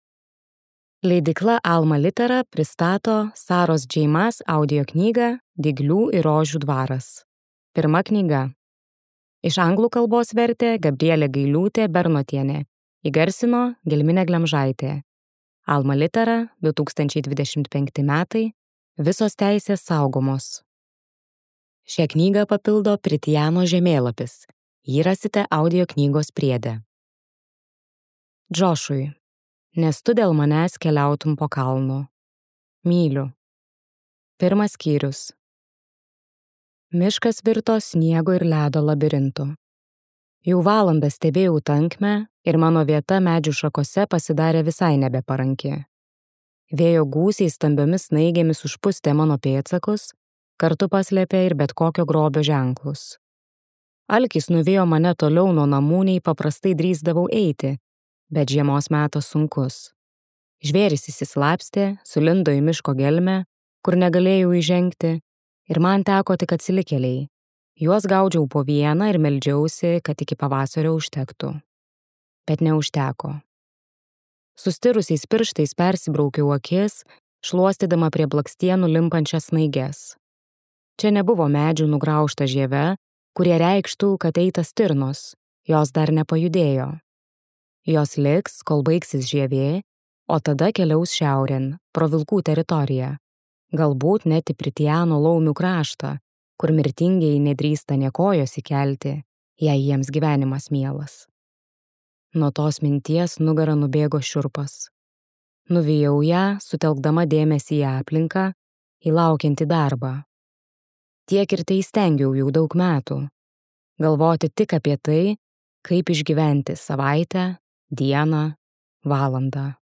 Dyglių ir rožių dvaras. Pirma knyga | Audioknygos | baltos lankos